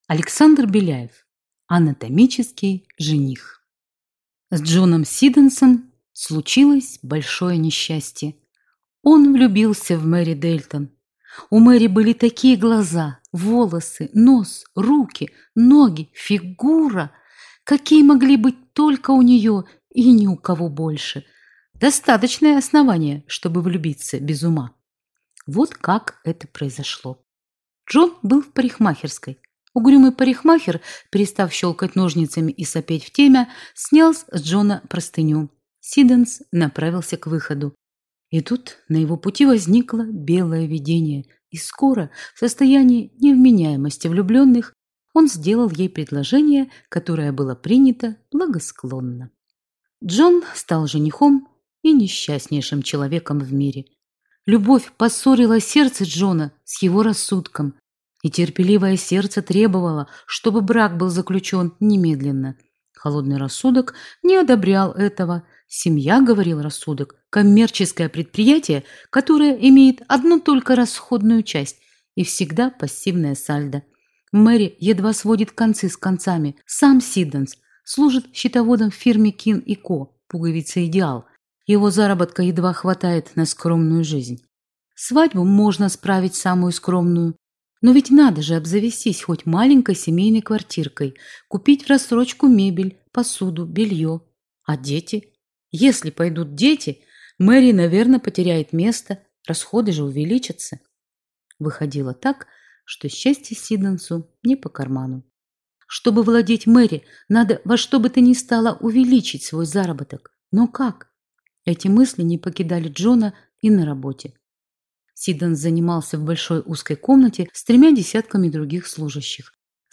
Аудиокнига Анатомический жених | Библиотека аудиокниг